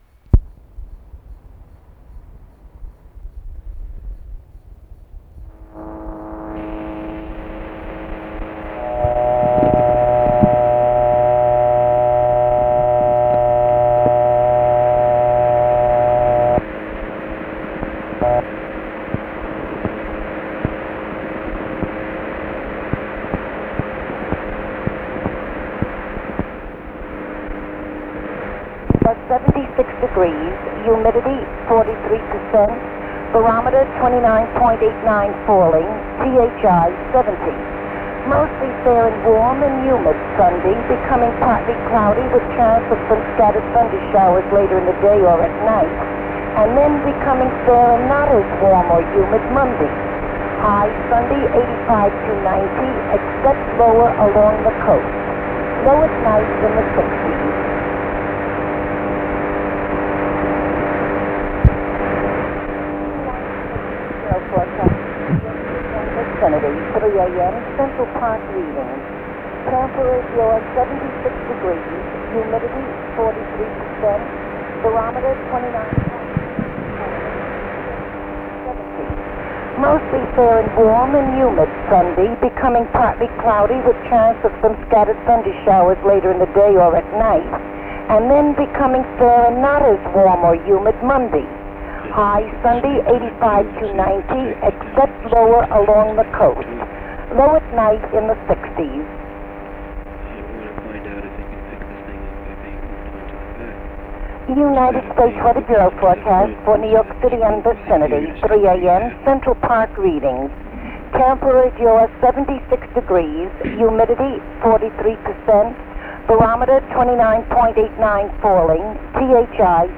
ok, it's the telco/weather bureau recording
of the weather report way back in ... 1961 [a]
early, semi portable, reel-to-reel tape recorders
He had a suction cup inductance coil which he
placed on phone handsets to record the calls...
1961-weather.wav